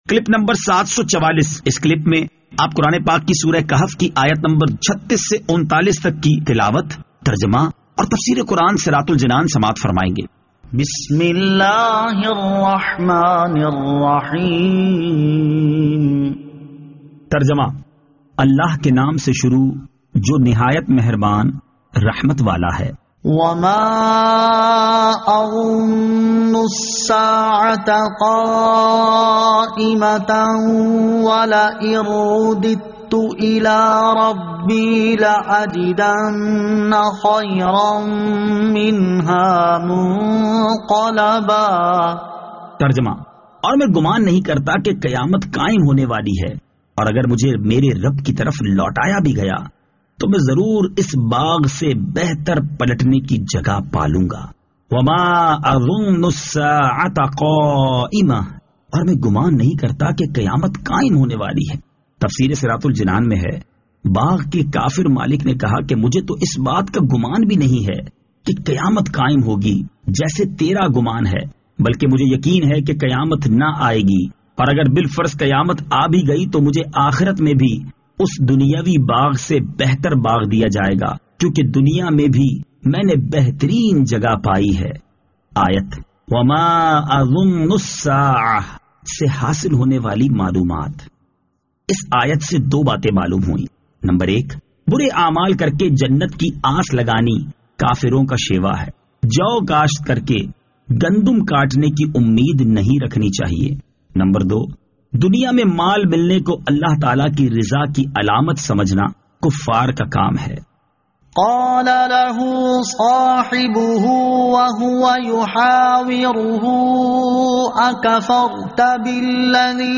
Surah Al-Kahf Ayat 36 To 39 Tilawat , Tarjama , Tafseer